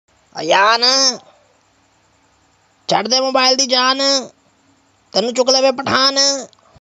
Goat